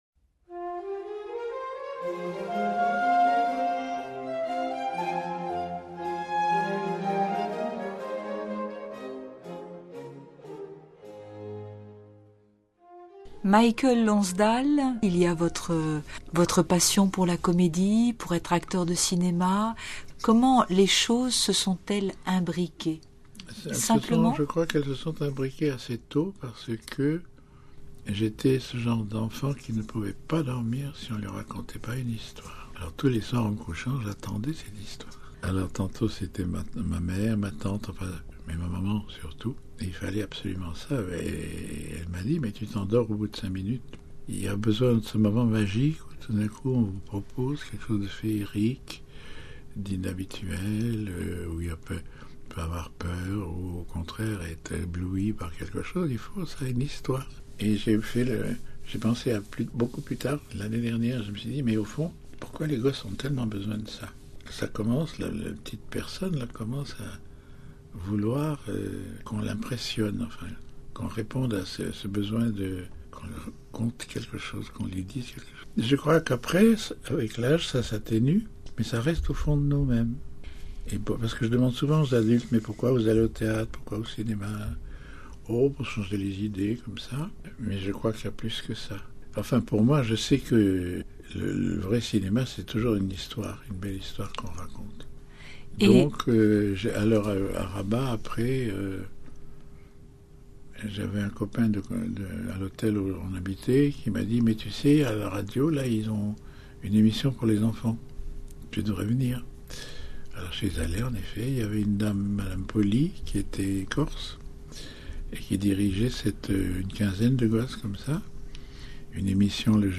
Entretien avec M-Lonsdale sur Vatican News